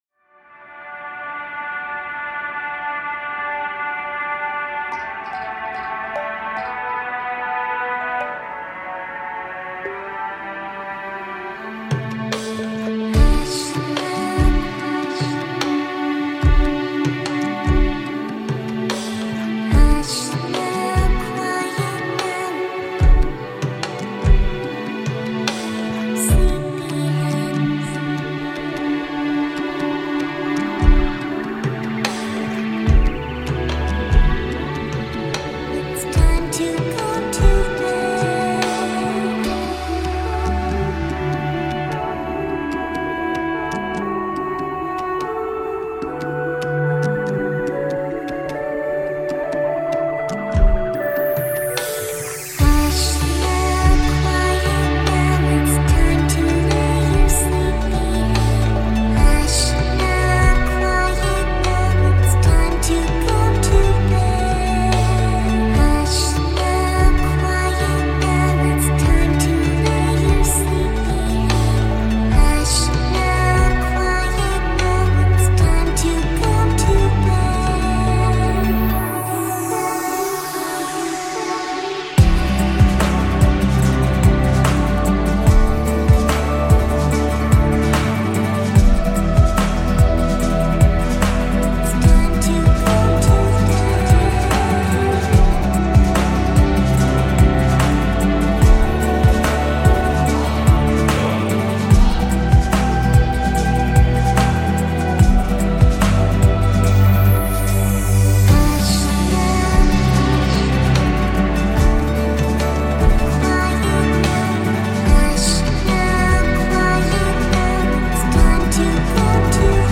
flute, percussion